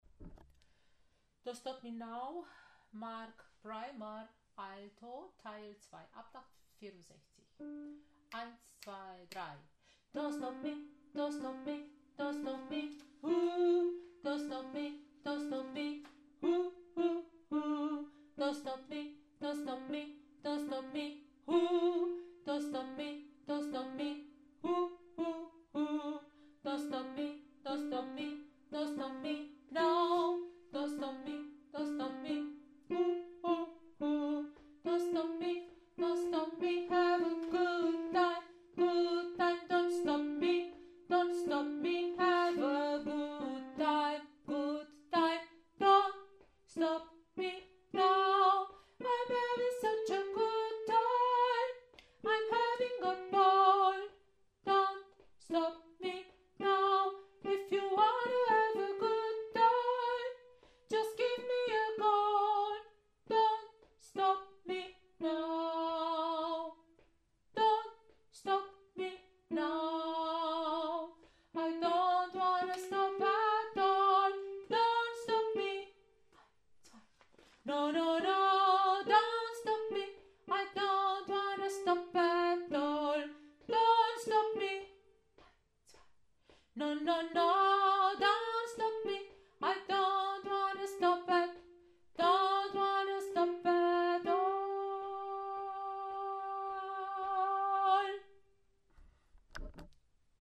Don’t stop me now Alto Teil 2